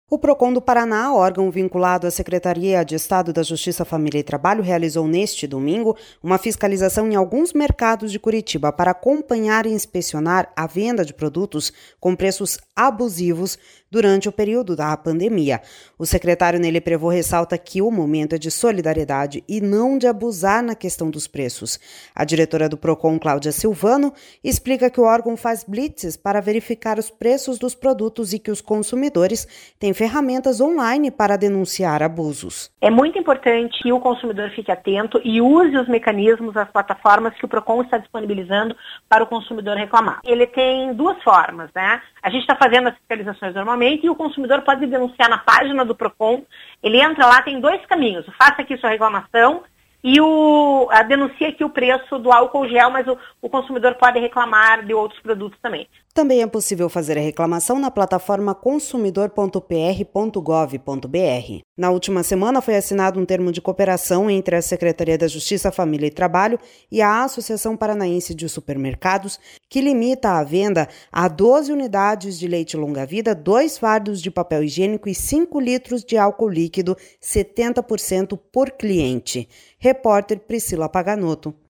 A diretora do Procon, Claudia Silvano, explica que o órgão faz blitzes para verificar os preços dos produtos e que os consumidores têm ferramentas online para denunciar abusos.// SONORA CLAUDIA SILVANO//